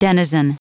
Prononciation du mot denizen en anglais (fichier audio)
Prononciation du mot : denizen